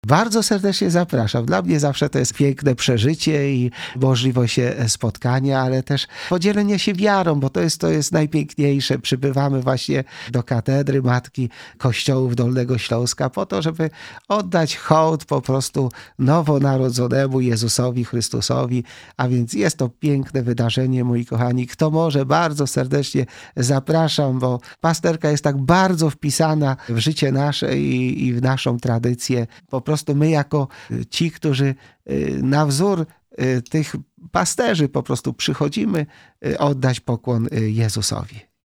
Jak przeżyć ten czas i doświadczyć Bożej Miłości? – pytamy ojca biskupa Jacka Kicińskiego.